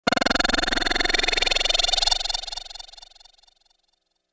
Teleportation Device.wav